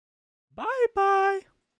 Бай-бай мужской голос